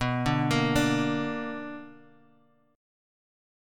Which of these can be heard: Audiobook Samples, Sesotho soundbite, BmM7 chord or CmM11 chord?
BmM7 chord